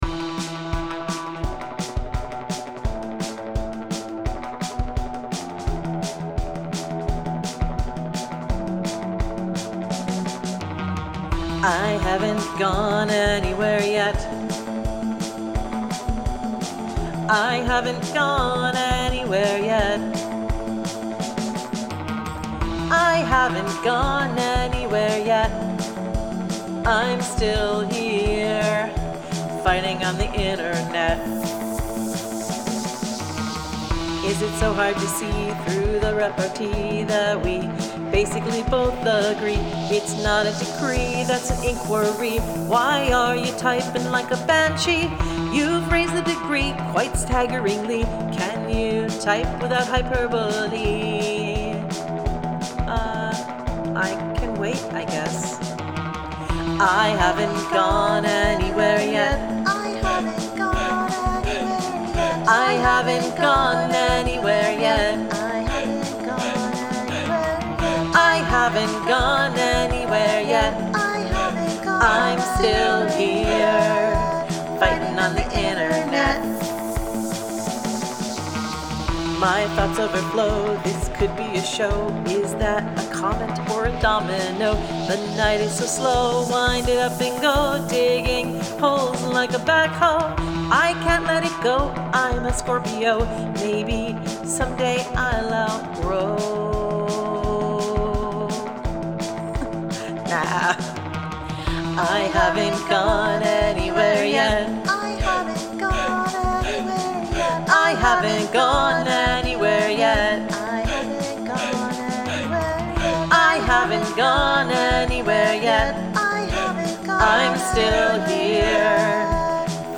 Built in GarageBand
surf guitar loop